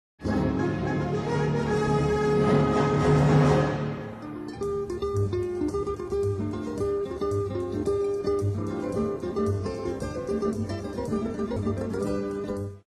Gitarren mit Orchester